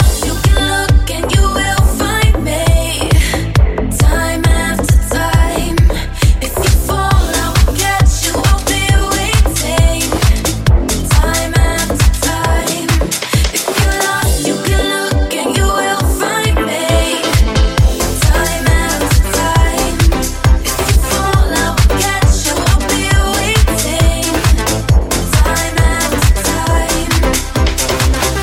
Genere: deep, house, club, remix